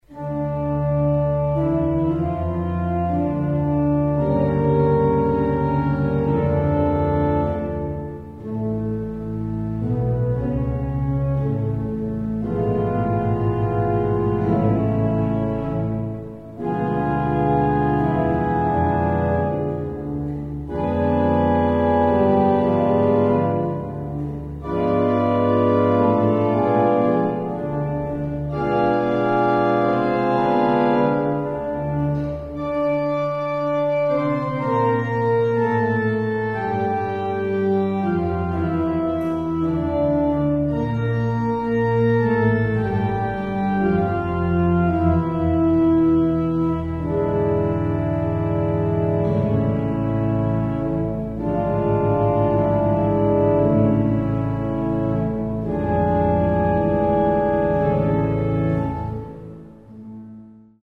ORGELN
Byggd 1867 av Frans Andersson, Stockholm.